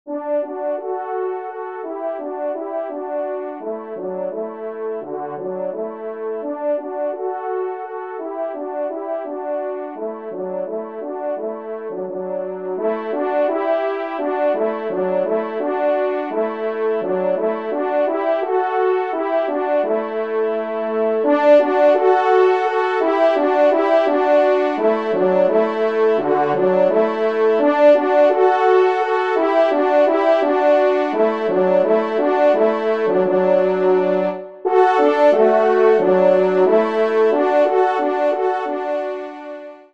2ème Trompe